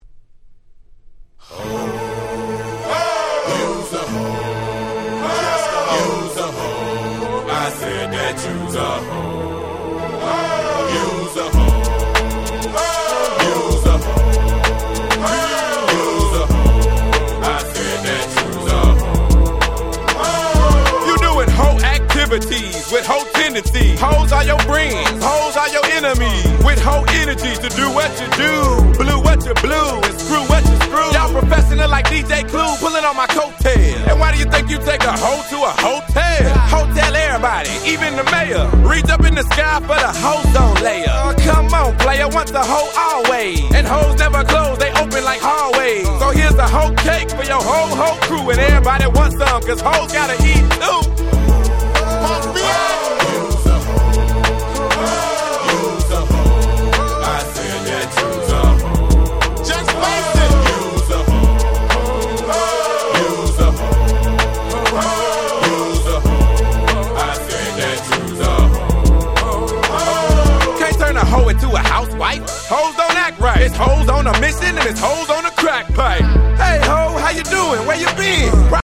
00' Super Hit Southern Hip Hop !!